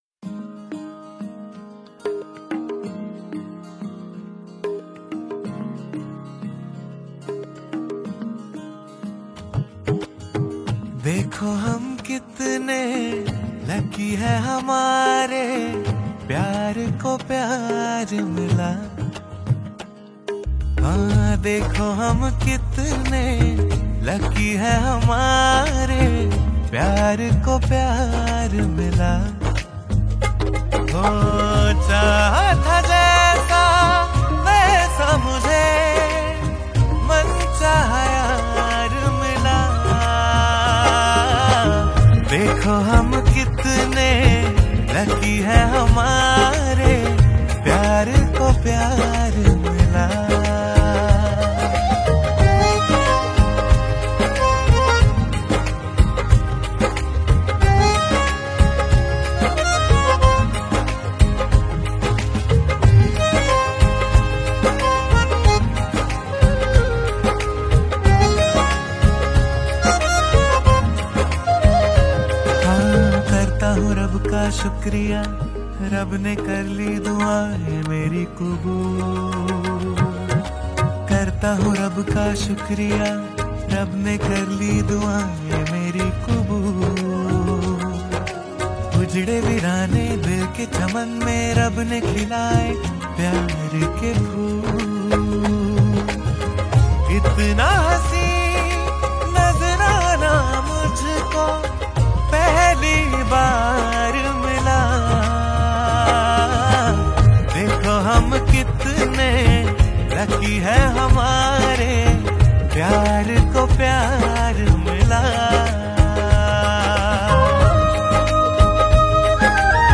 Hindi Pop Album Songs 2023